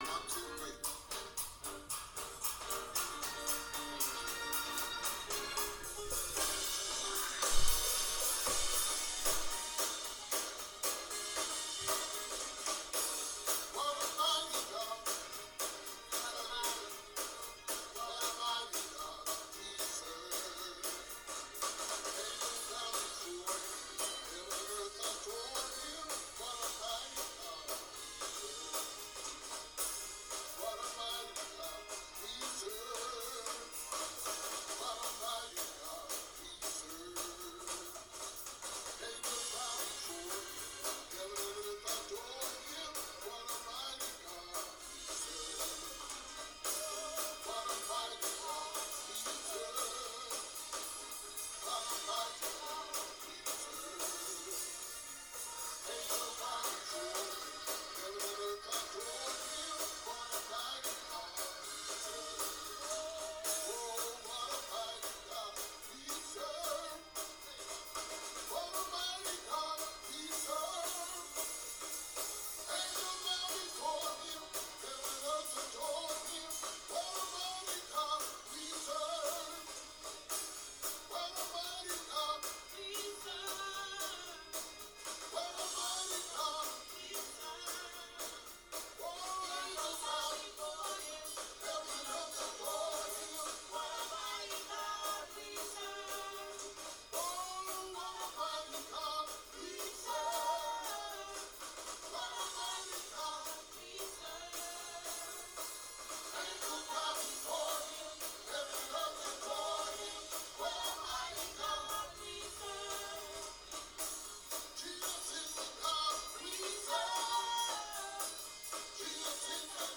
C# Bb 4/4 100 Congregational Devotion Praise Gospel Congregational